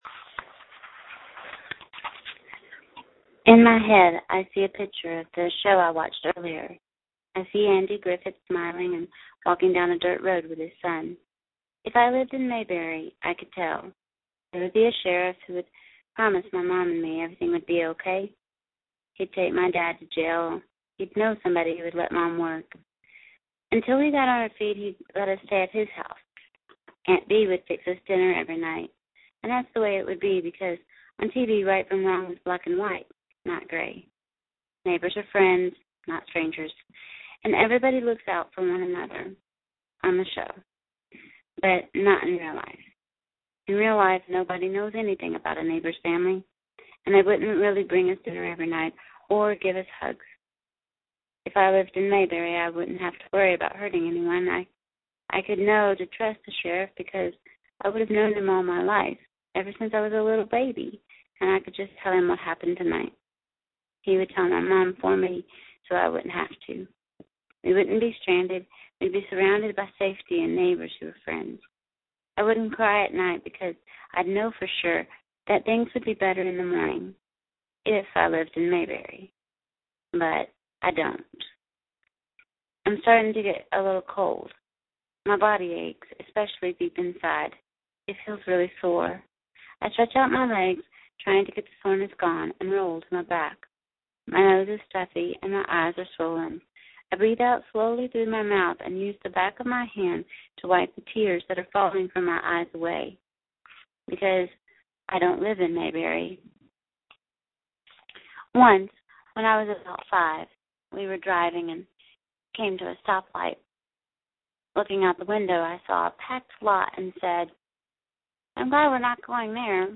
Audio of the Speech
So, tonight, I am posting an audio reading of the speech again.